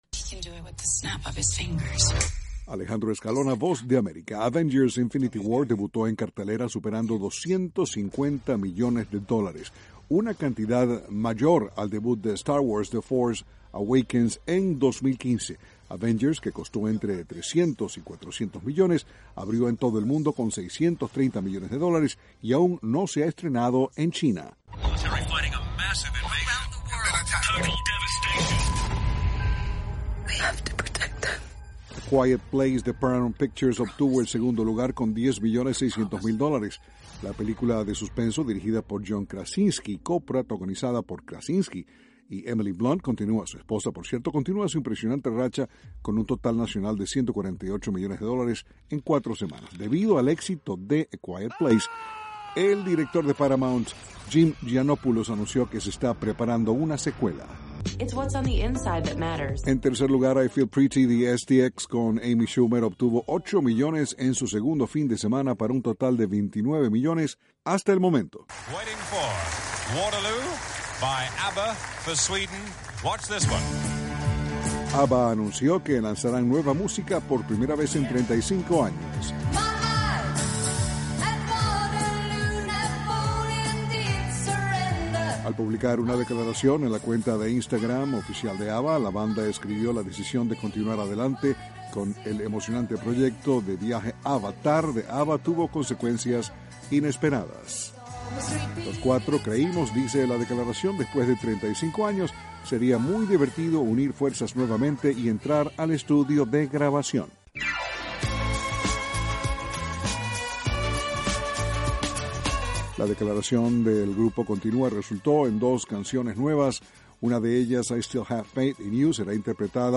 informa desde Washington...